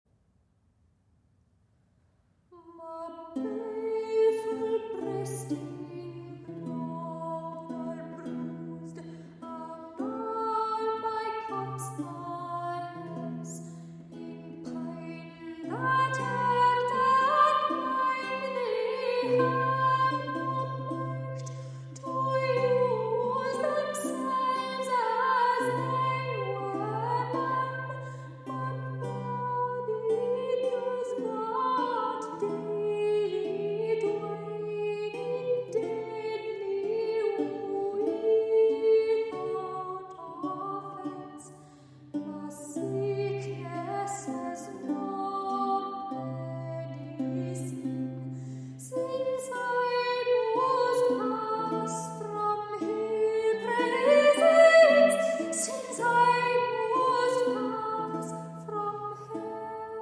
Soprano
Renaissance Lute